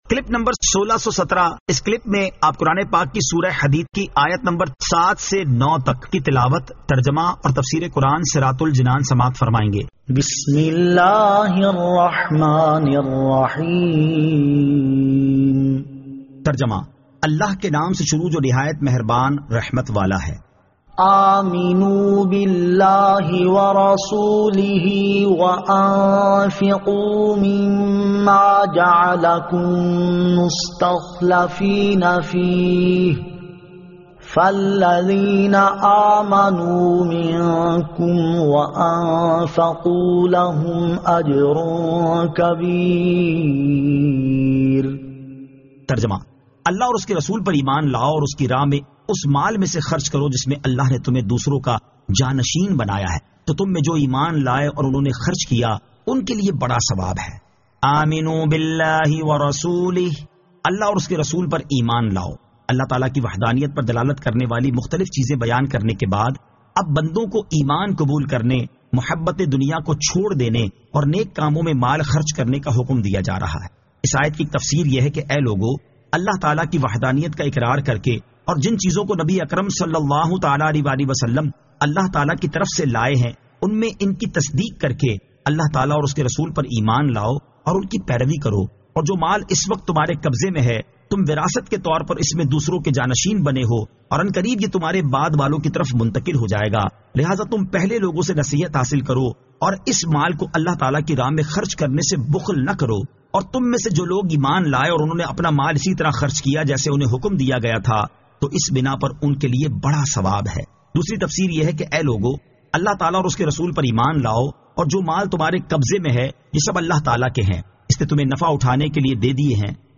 Surah Al-Hadid 07 To 09 Tilawat , Tarjama , Tafseer